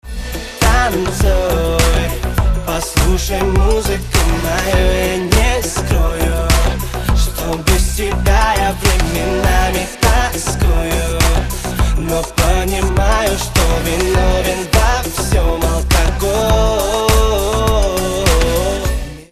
• Качество: 128, Stereo
поп
мужской вокал
dance